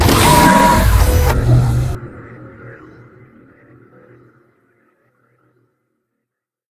combat / enemy / droid